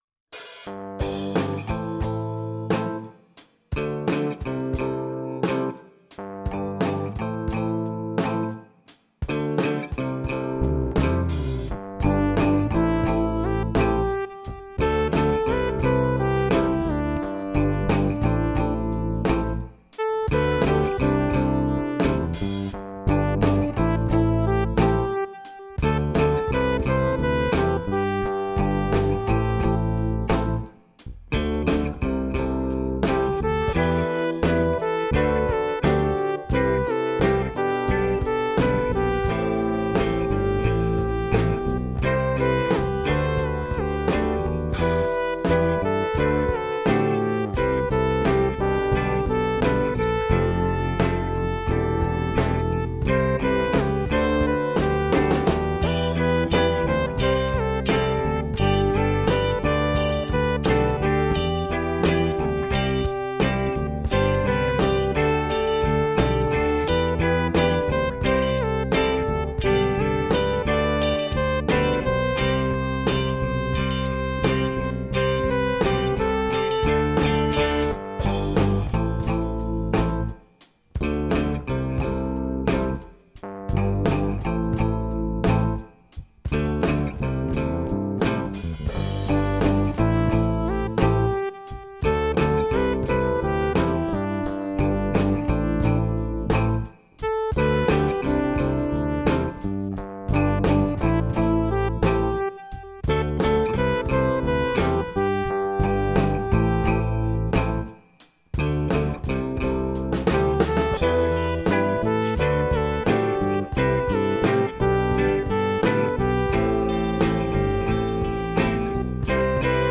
Groove
It doesn’t groove as much as I would like because this recording is a little slow!
groove.mp3